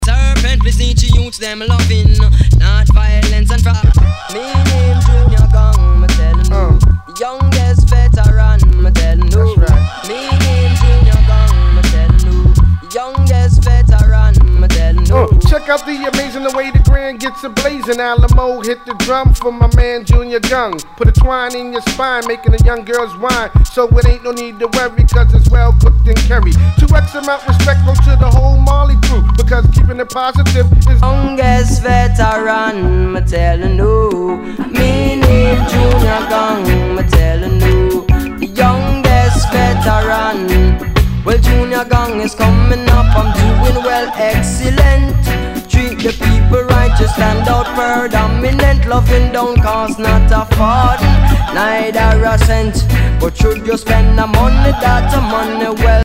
類別 雷鬼
ジャケにスレキズ、角潰れあり、全体にチリノイズが入ります